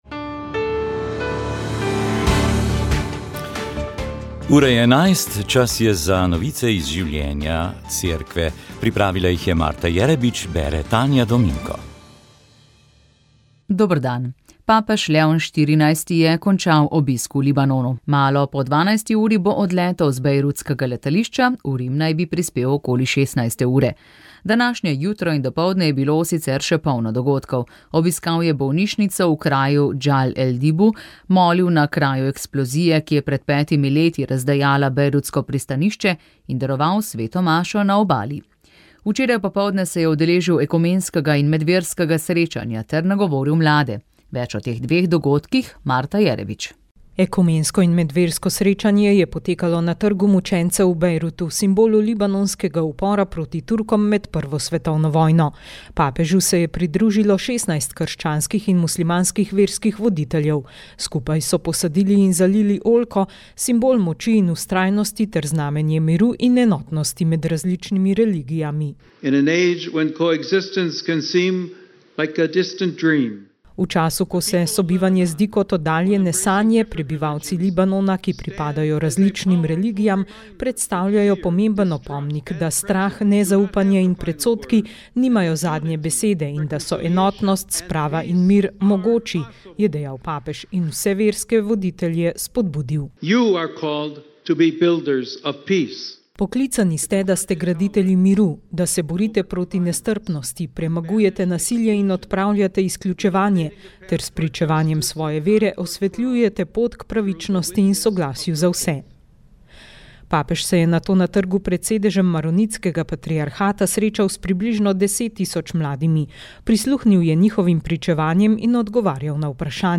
Informativne oddaje